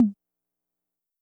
Tom 4